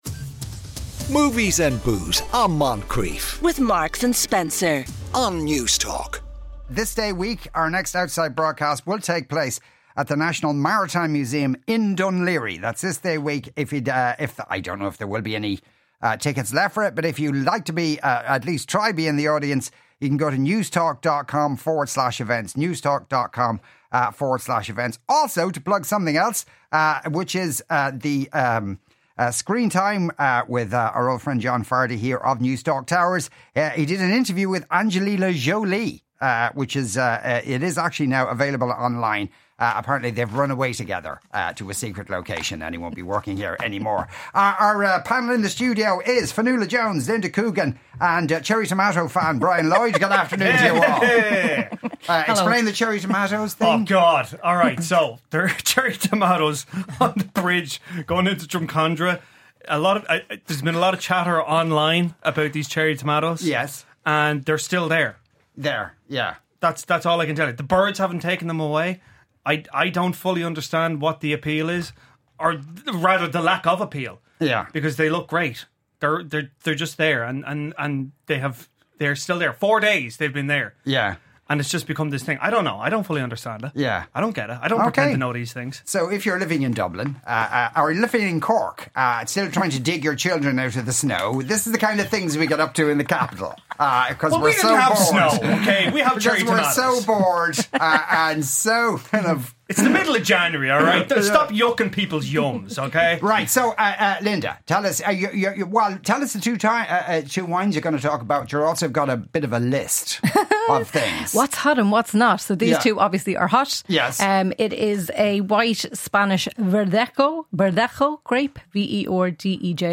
Each week in a relaxed and casual environment the three chat about movies over some beer or wine just like being in a pub or at a dinner party!